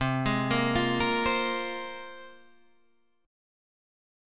C7sus4#5 chord